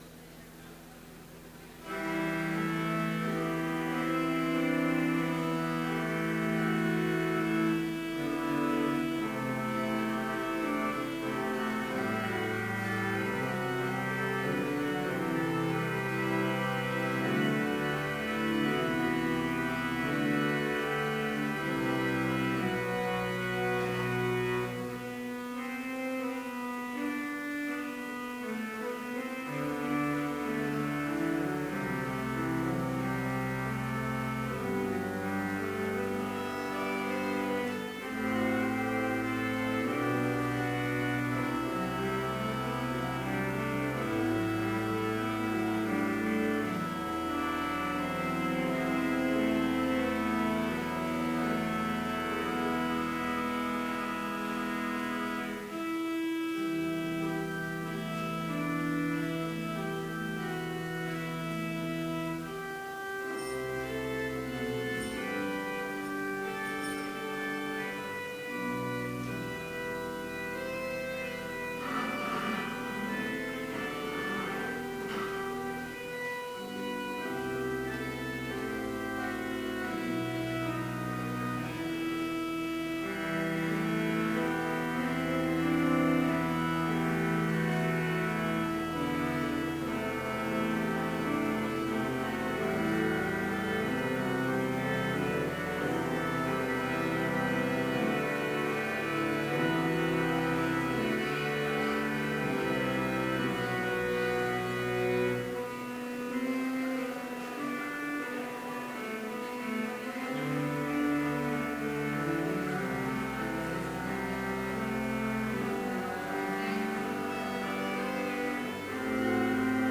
Complete service audio for Chapel - November 9, 2015